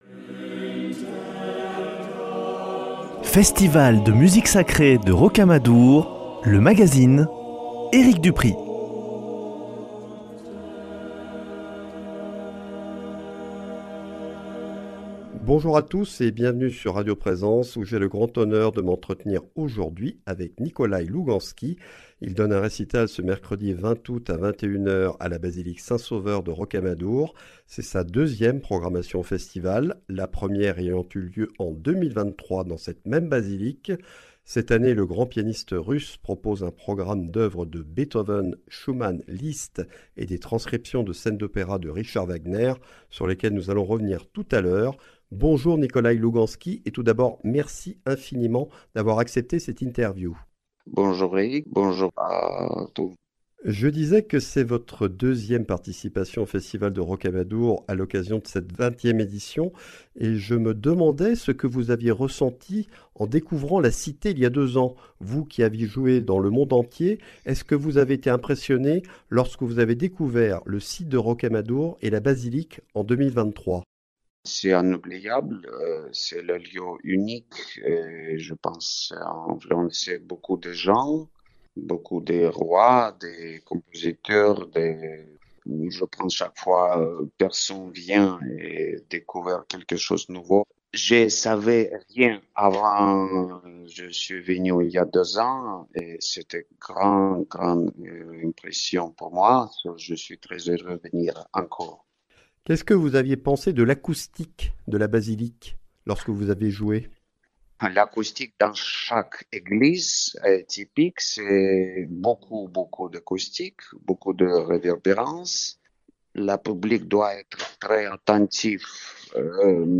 Entretien avec un maître du clavier, au cours duquel il revient sur ses années de formation, son admiration pour Wagner et sa passion pour le jeu d’échecs.